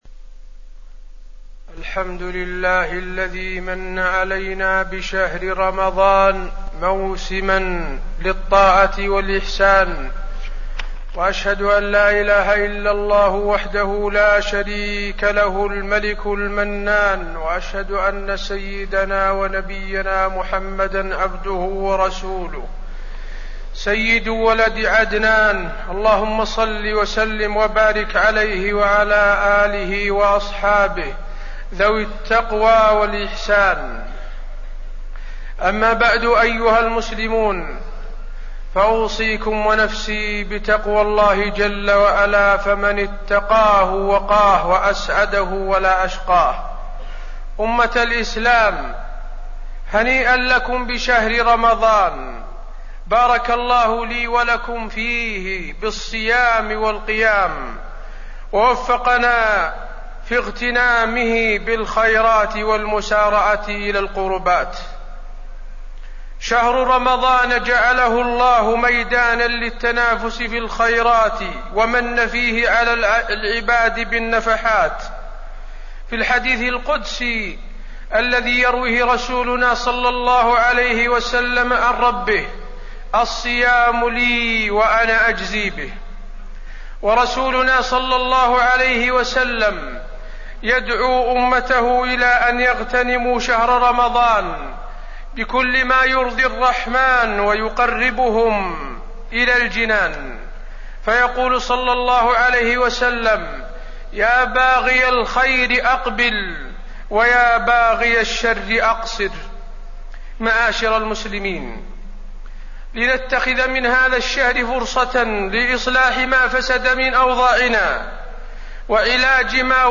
تاريخ النشر ٣٠ شعبان ١٤٣٠ هـ المكان: المسجد النبوي الشيخ: فضيلة الشيخ د. حسين بن عبدالعزيز آل الشيخ فضيلة الشيخ د. حسين بن عبدالعزيز آل الشيخ دخول رمضان